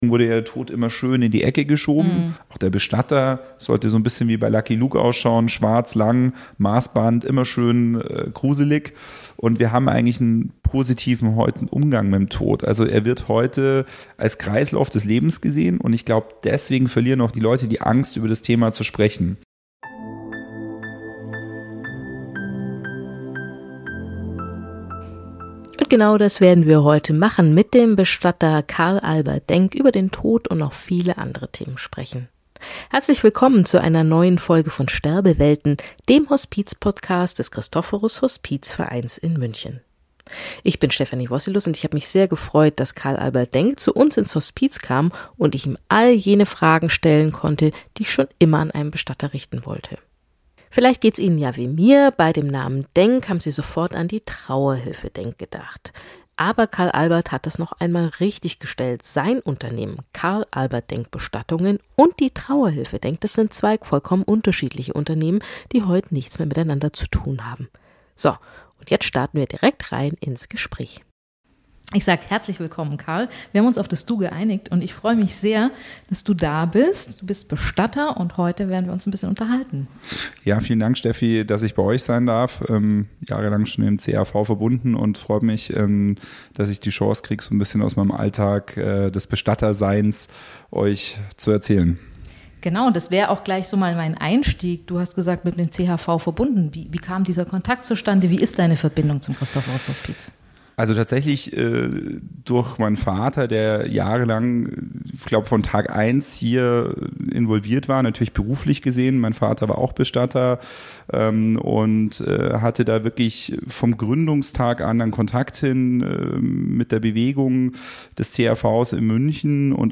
Im Gespräch mit einem Bestatter ~ Sterbewelten - der Hospizpodcast Podcast